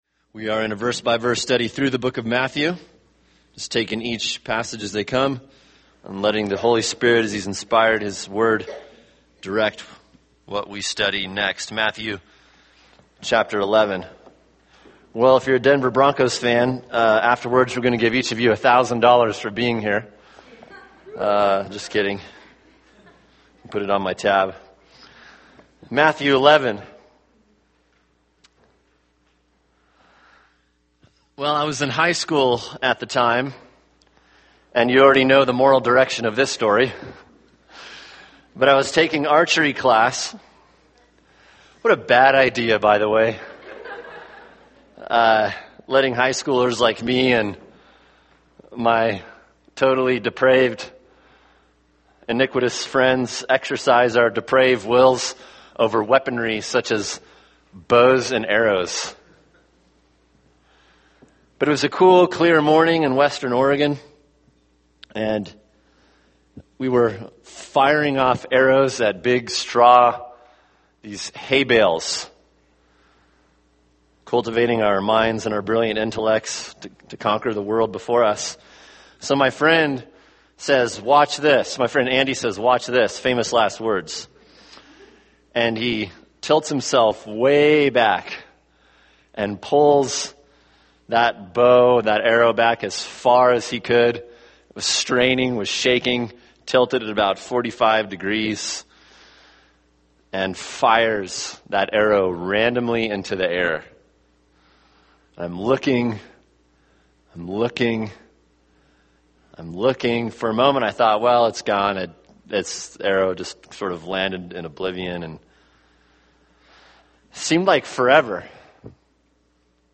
[sermon] Matthew 11:25-30 – God’s Sovereign Grace (part 1) | Cornerstone Church - Jackson Hole